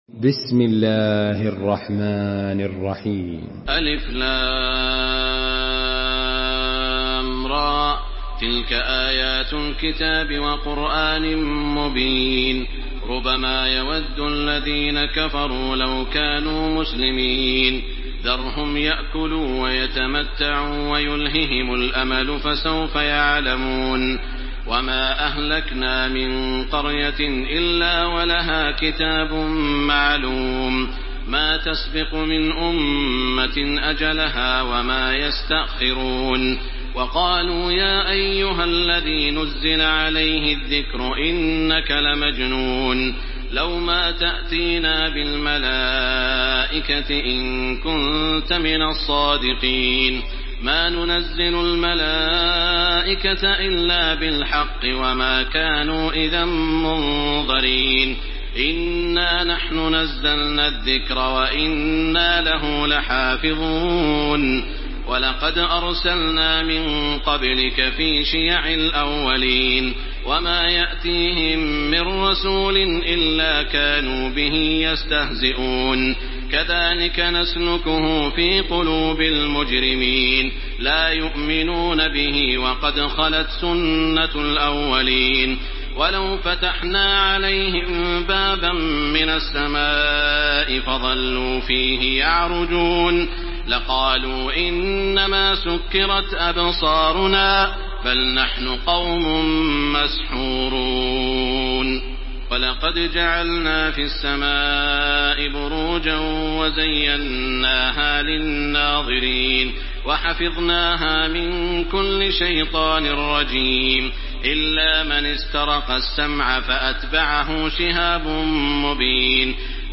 سورة الحجر MP3 بصوت تراويح الحرم المكي 1429 برواية حفص
مرتل